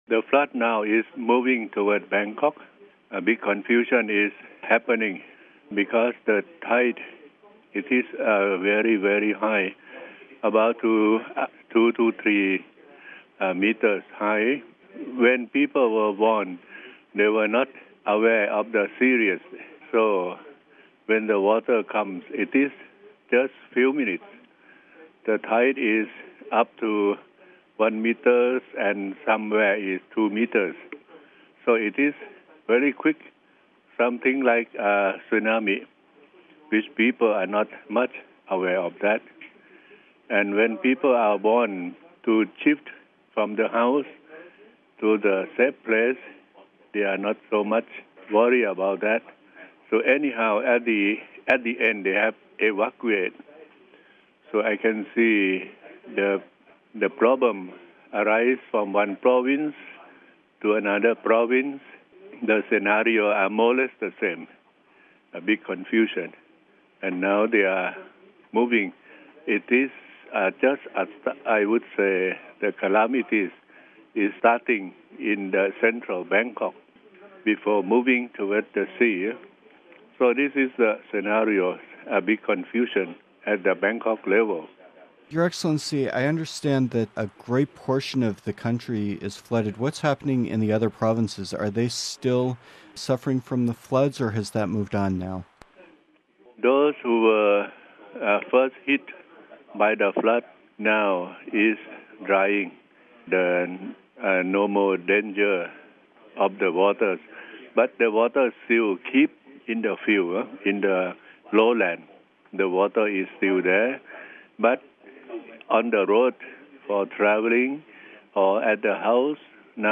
Listen to the full interview of Bishop Joseph Pibul